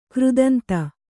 ♪ křdanta